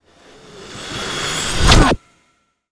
magic_conjure_charge2_05.wav